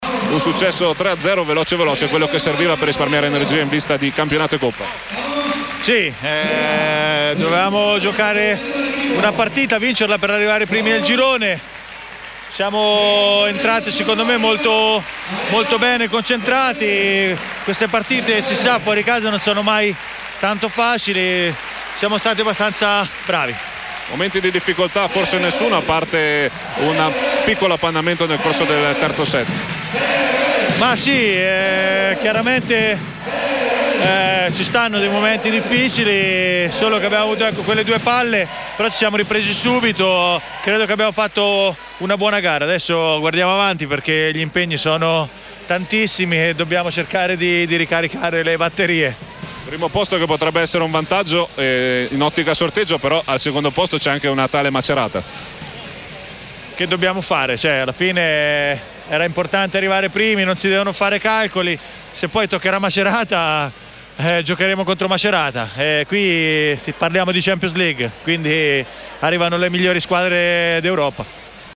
Interviste mp3